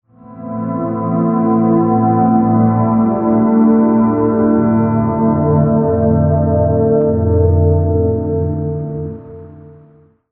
Old Synth (142 kb, mp3, 10 sec)
This was recorded only with the GSP2101 directly into the computer, the guitar was a 12 string acoustic Ibanez (which causes the white noise at the end of the recording).
The sound is quite similar to the old 70's analog synths.
This is ambient to the max.